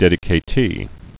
(dĕdĭ-kā-tē)